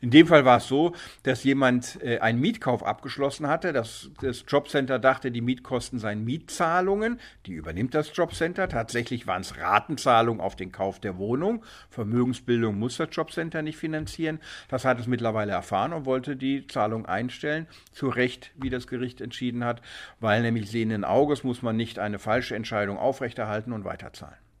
O-Ton: Hartz-lV-Leistungen während Corona nicht in jedem Fall